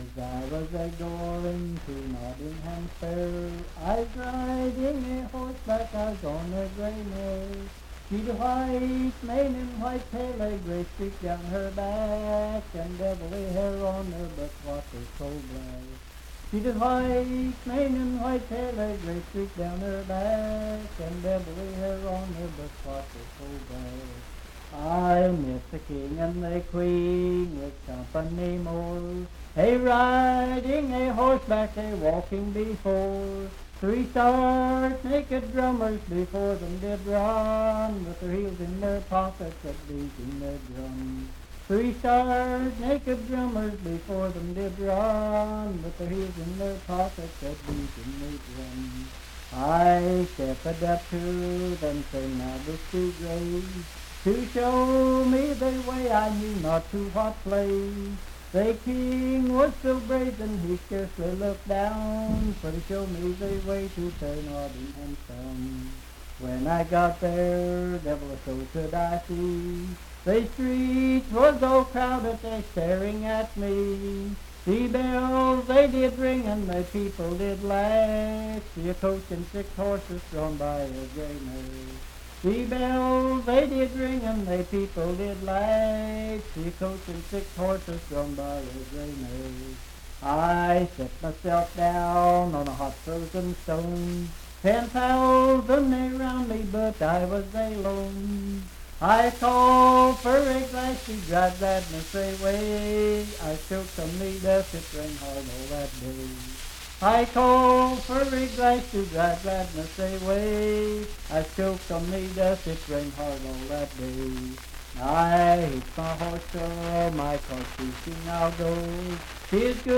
Unaccompanied vocal music
in Riverton, Pendleton County, WV.
Verse-refrain 6(6).
Humor and Nonsense, Children's Songs
Voice (sung)